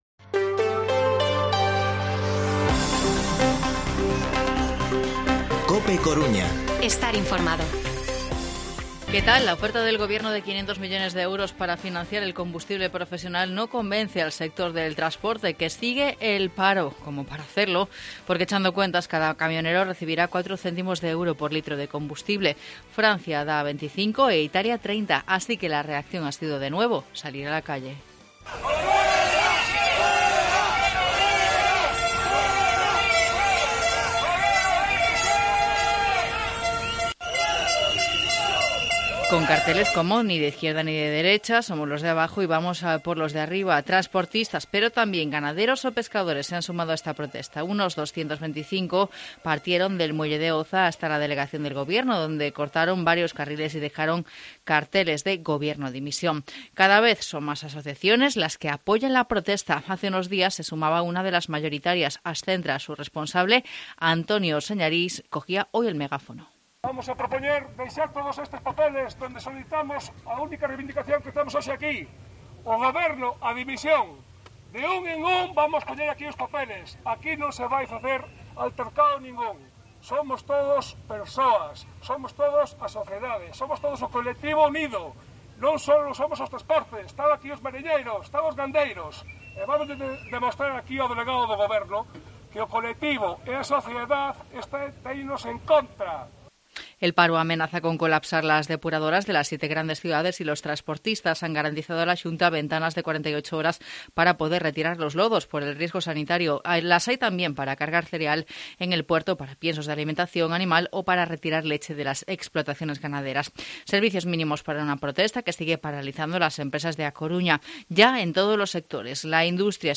Informativo Mediodía COPE Coruña martes, 22 de marzo de 2022 14:20-14:30